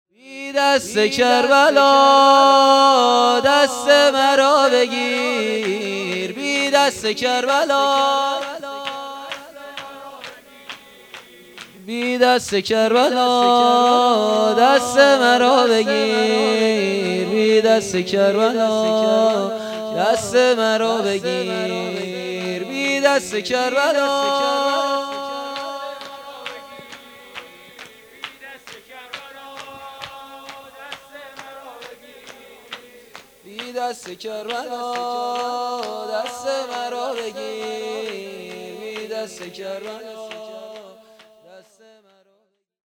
شور | ذکر | بی دست کربلا دست مرا بگیر
صوت هیئت هفتگی 1400/7/8